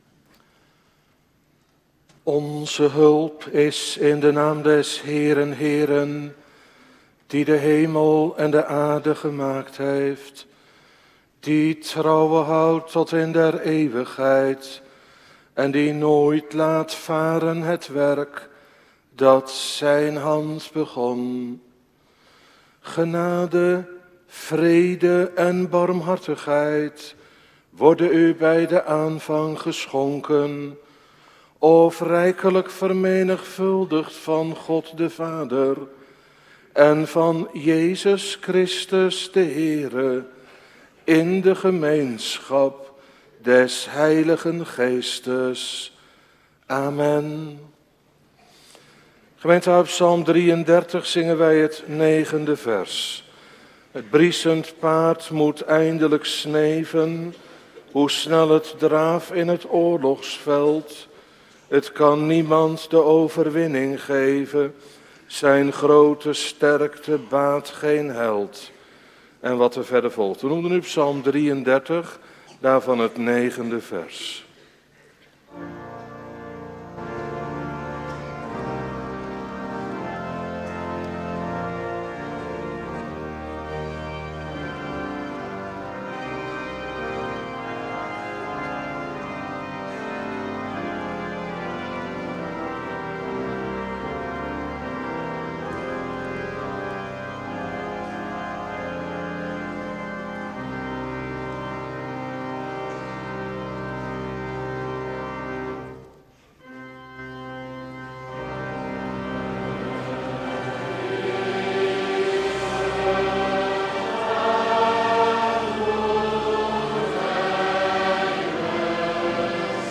Preken terugluisteren